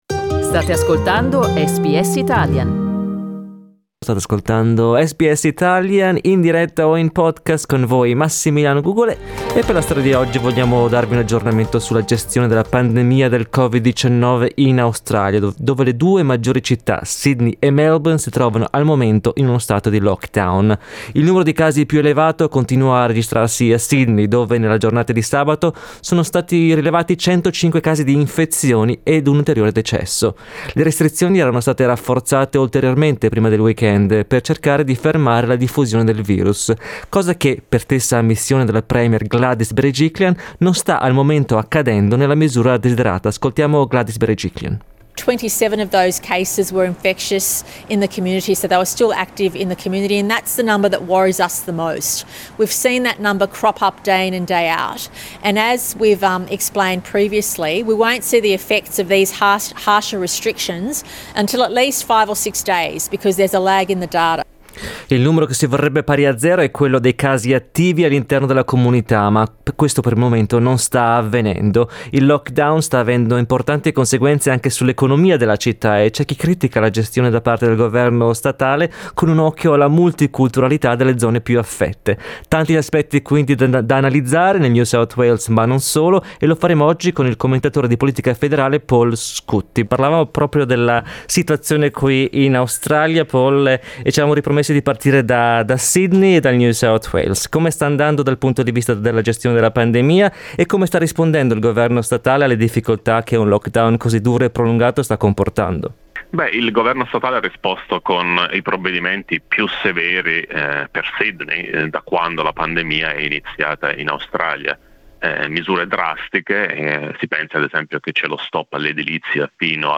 Ascolta l'aggiornamento di questa mattina